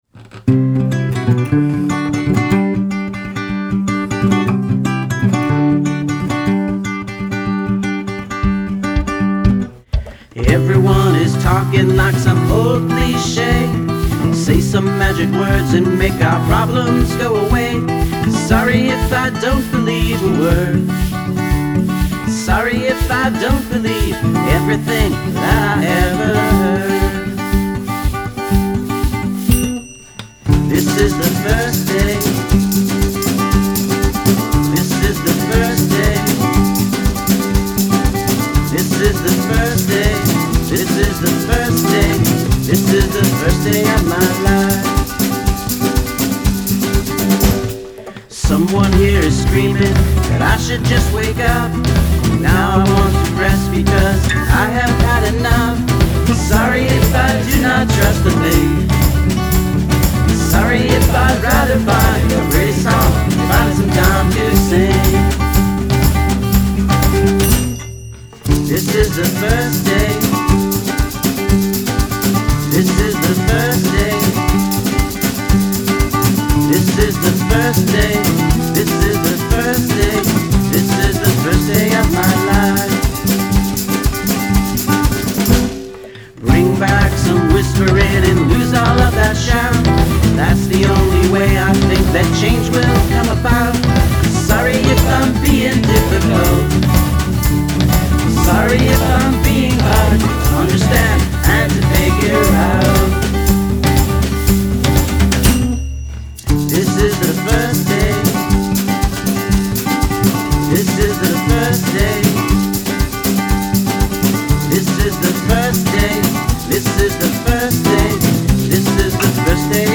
boston's power duo